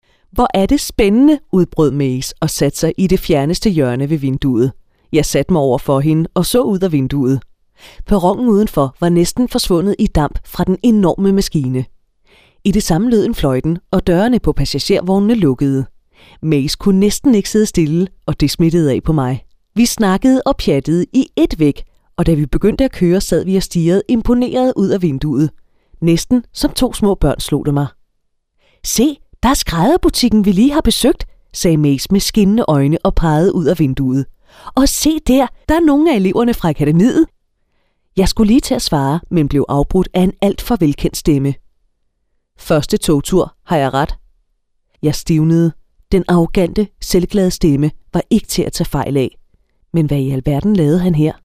Sprecherin dänisch.
Sprechprobe: Werbung (Muttersprache):
Female danish Voice over artist with many years of experience.